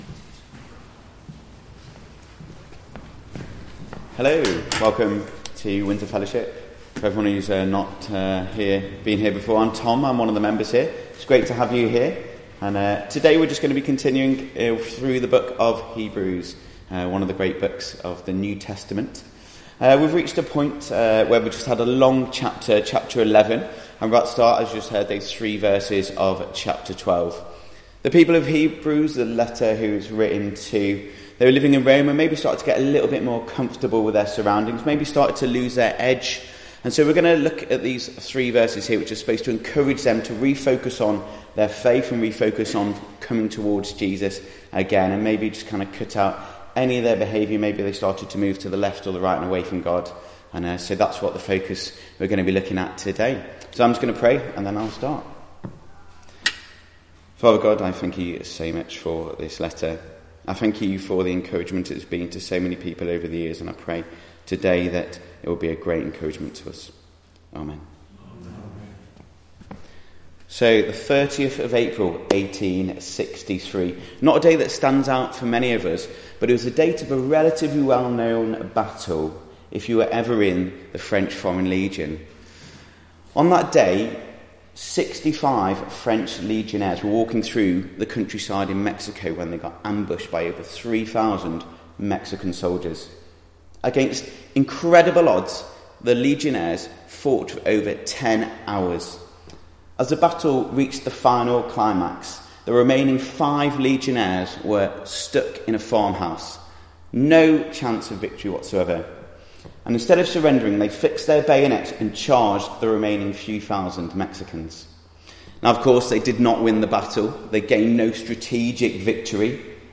Hebrews Passage: Hebrews 12: 1-3 Service Type: Weekly Service at 4pm Bible Text